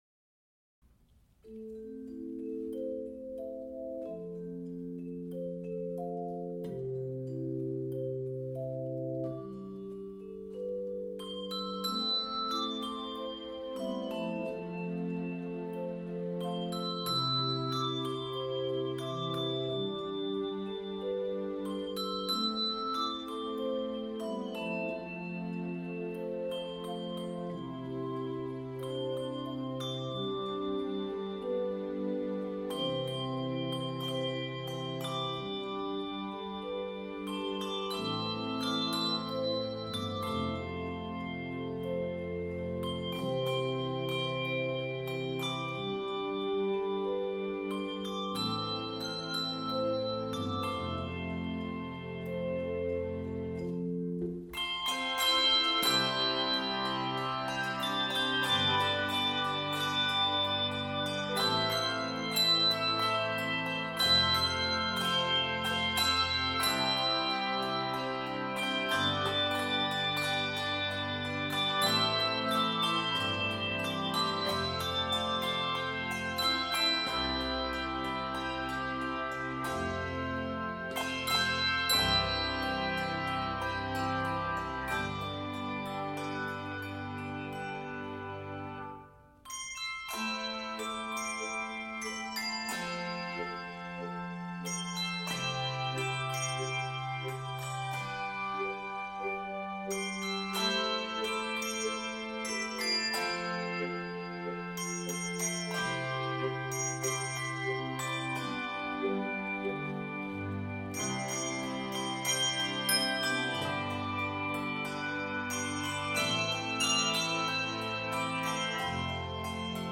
contemporary Easter hymn
handbells